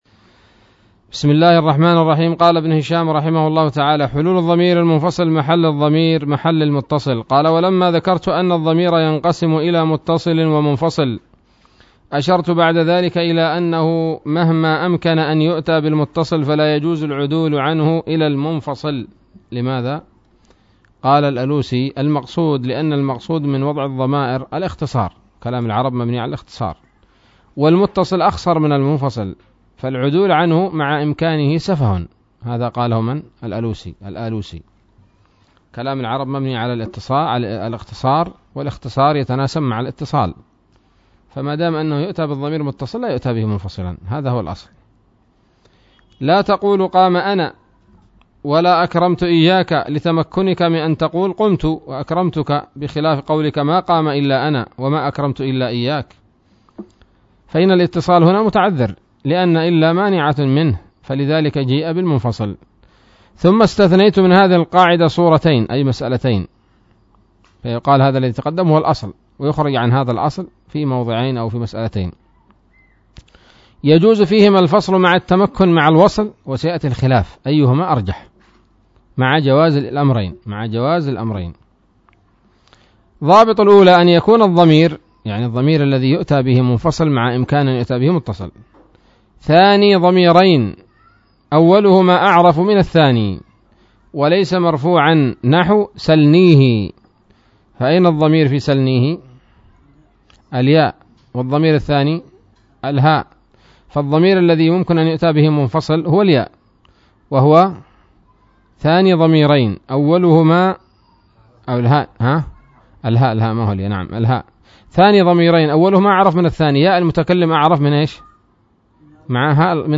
الدرس الثاني والأربعون من شرح قطر الندى وبل الصدى